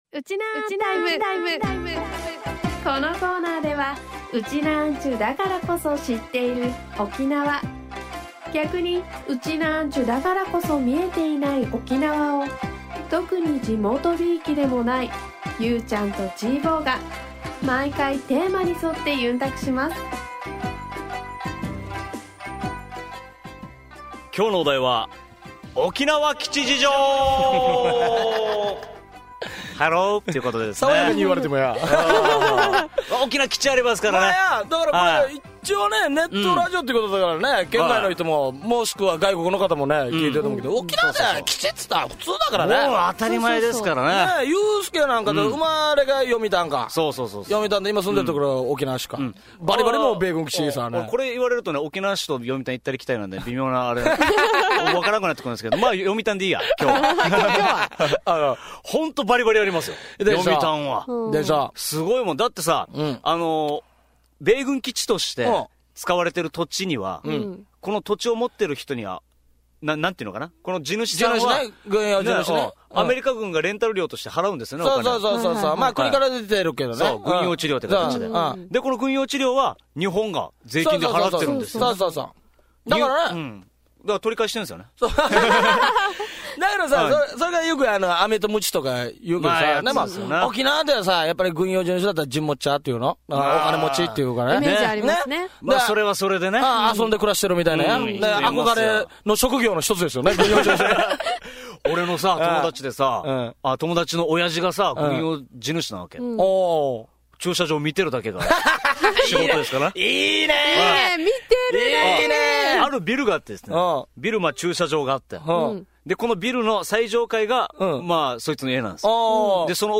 沖縄から全世界へ配信しているインターネット・ラジオ